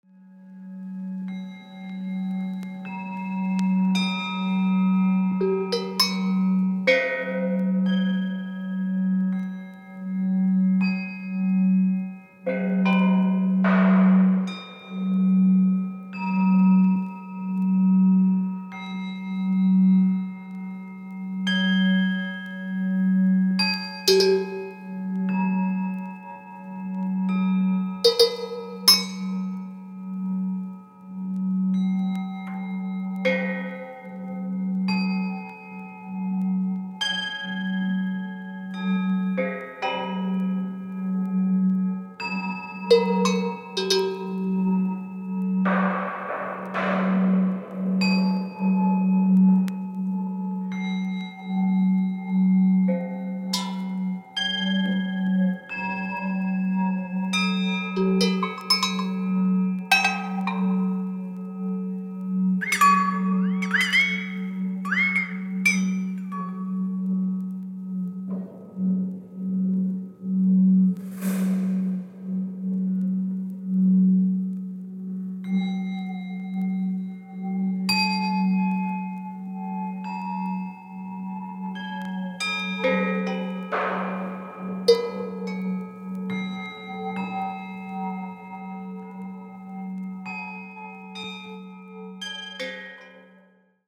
静寂、瞬発力、人間の感情を上手に表現されてます。